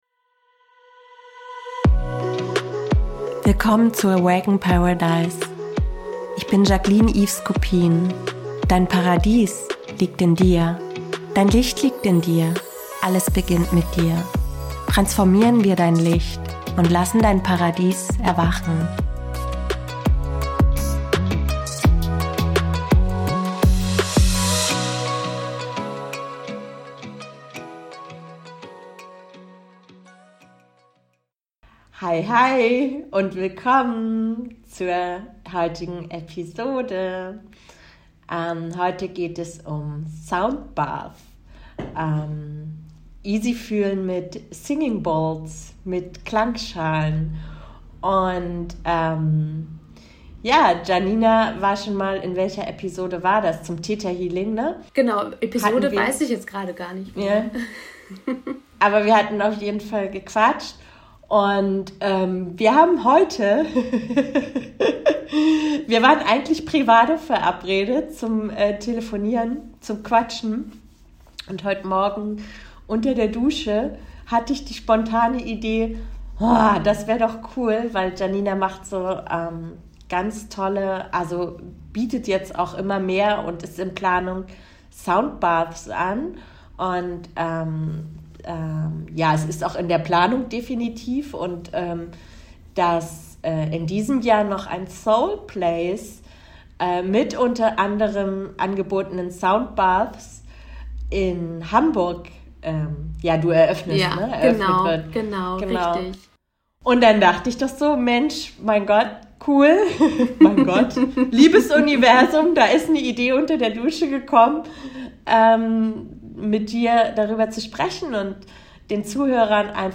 Zum Easy Fühlen ein Singing Bowls Soundbath für dich!